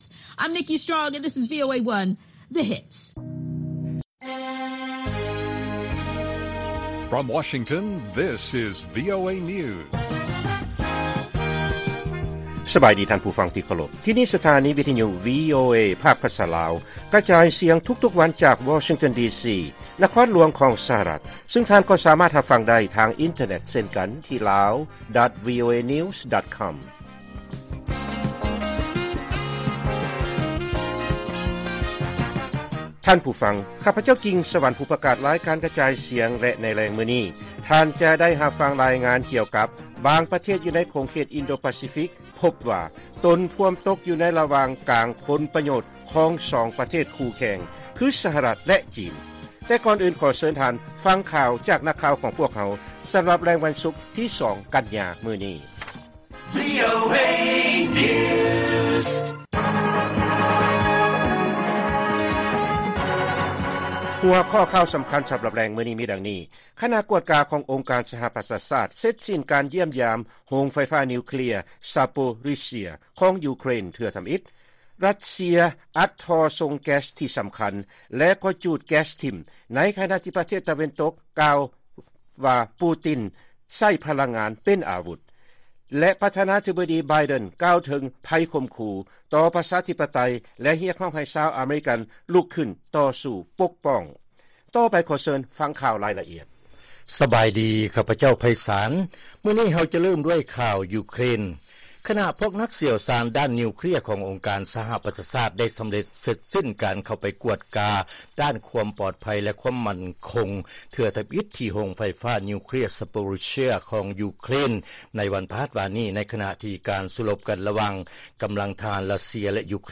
ລາຍການກະຈາຍສຽງຂອງວີໂອເອ ລາວ: ຄະນະກວດກາຂອງອົງການສະຫະປະຊາຊາດ ເສັດສິ້ນການຢ້ຽມຢາມໂຮງໄຟຟ້ານິວເຄລຍ ຊາໂປຣິສເຊຍ ຂອງຢູເຄຣນ ເທື່ອທຳອິດ